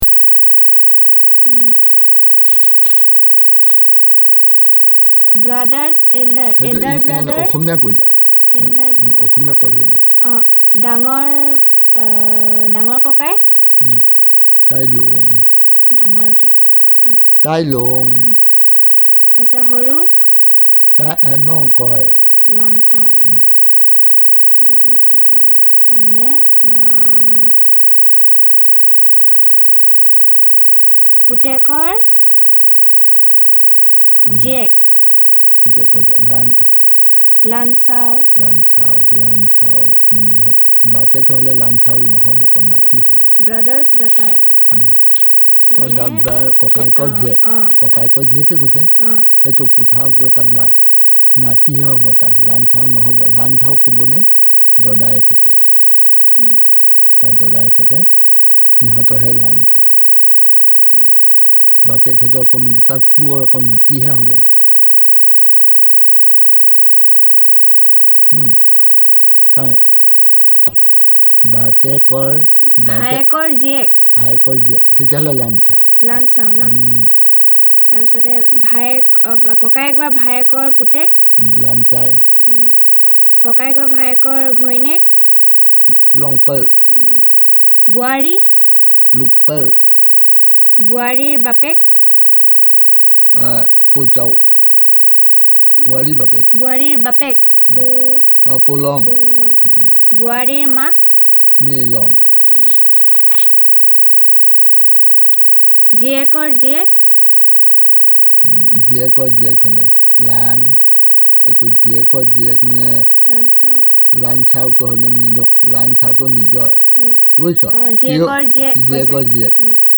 Elicitation of words about kinship terms.